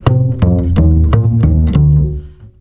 StickBass 6 F.wav